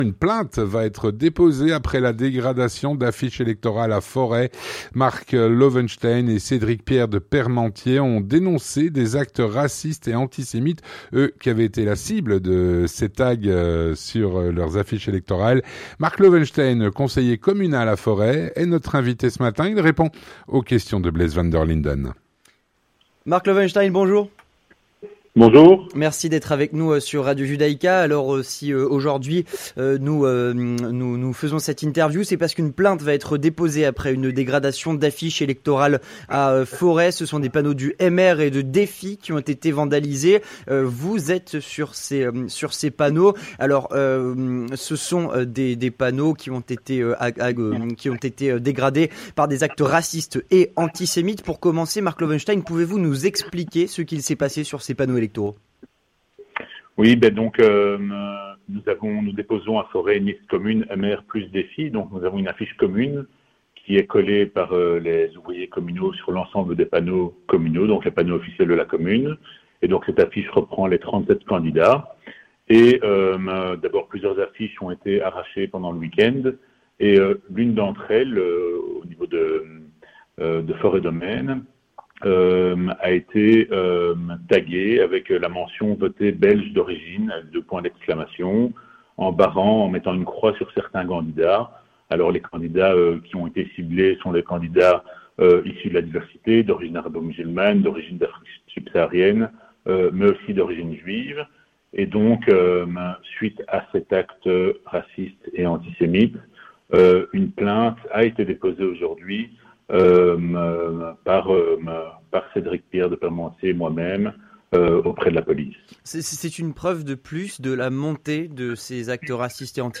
L'entretien du 18H - Une plainte va être déposée après la dégradation d'affiches électorales à Forest.
Marco Loewenstein, conseiller communal à Forest, est notre invité.